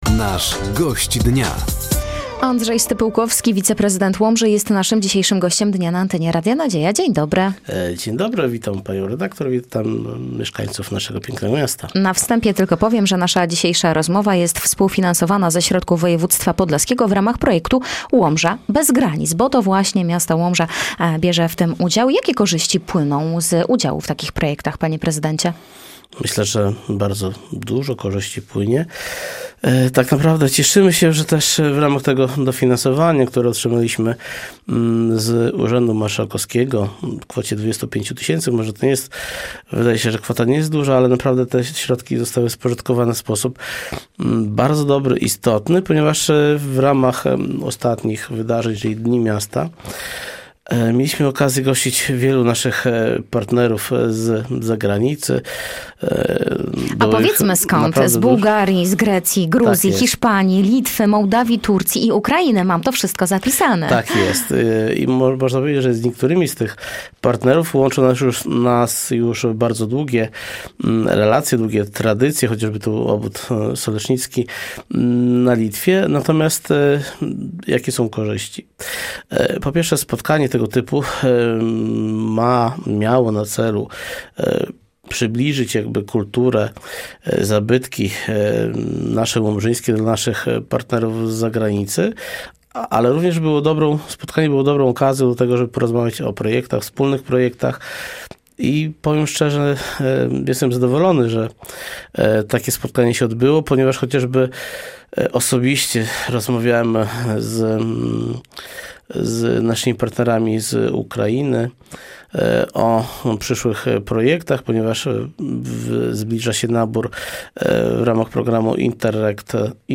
Gościem Dnia Radia Nadzieja był Andrzej Stypułkowski, wiceprezydent Łomży.